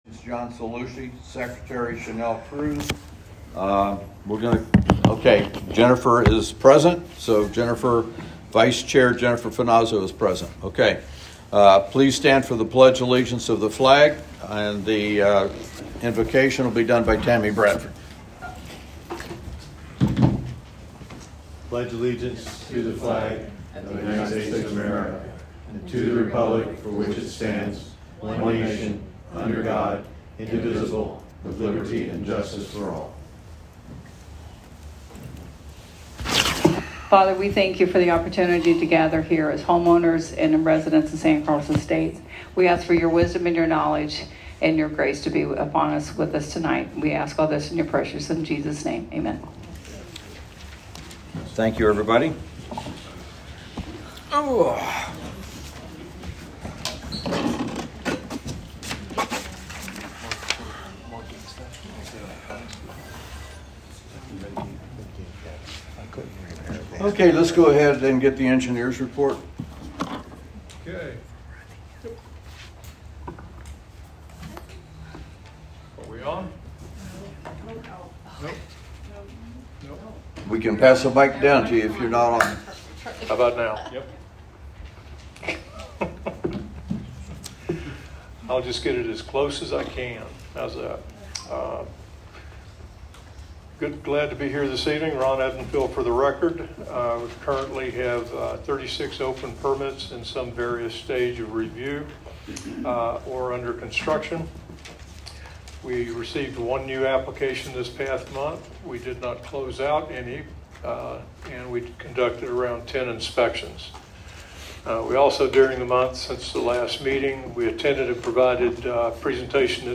Board Meetings: Gulf Shore Church, 25300 Bernwood Dr, Bonita Springs FL 34135